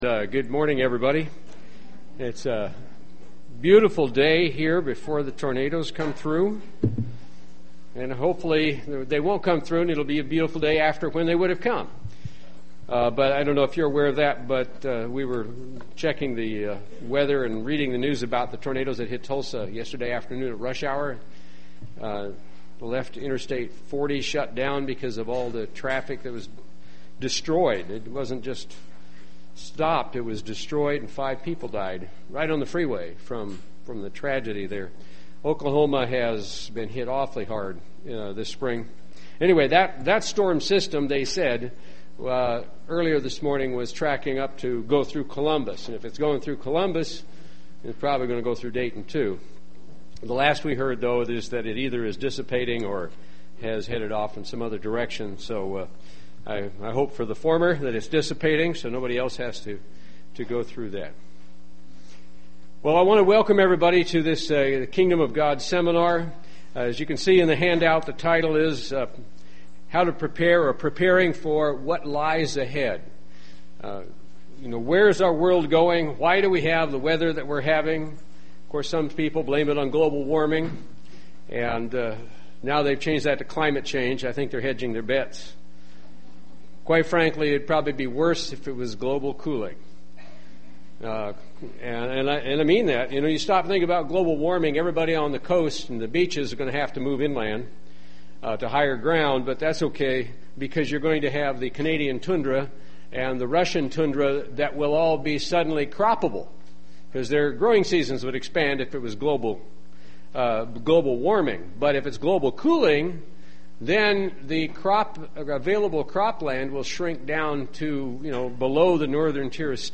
Learn more in this Kingdom of God seminar.
Given in Dayton, OH
UCG Sermon Studying the bible?